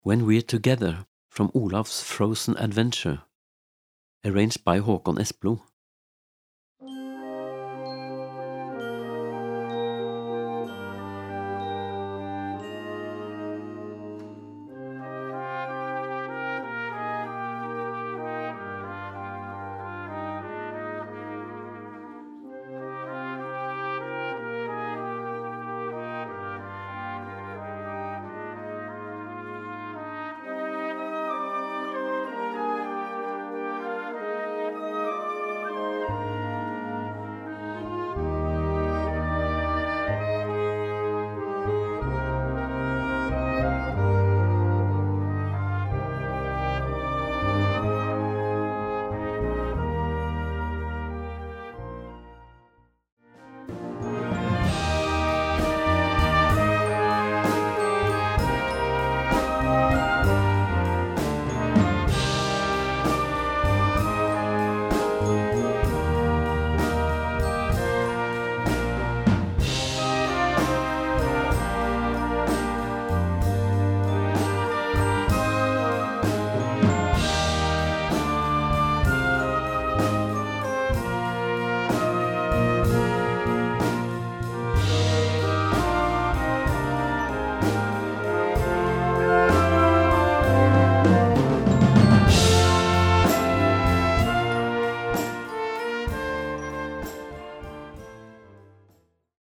Filmmusik für Jugendblasorchester
Besetzung: Blasorchester